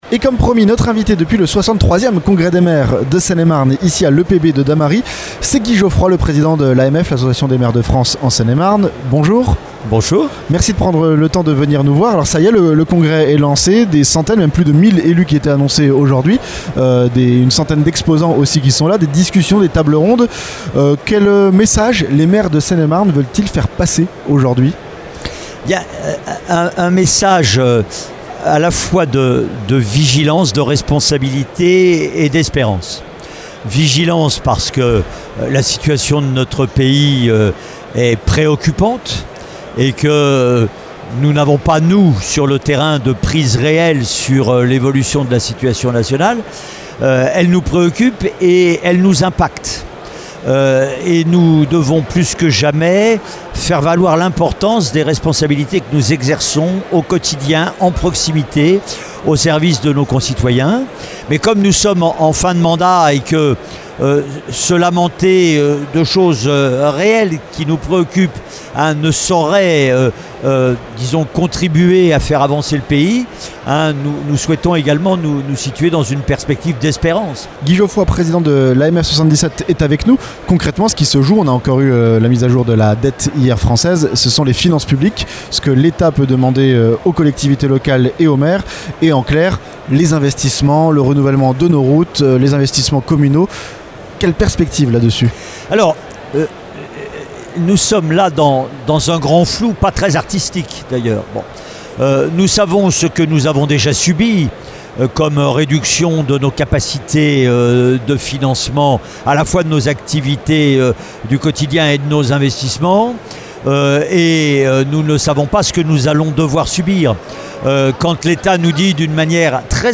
A l'occasion du 63ème congrès des maires de Seine-et-Marne, à Dammarie-lès-lys, Oxygène s'est entretenu avec Guy Geoffroy, Président de l'Association des maires de France (AMF) de Seine-et-Marne.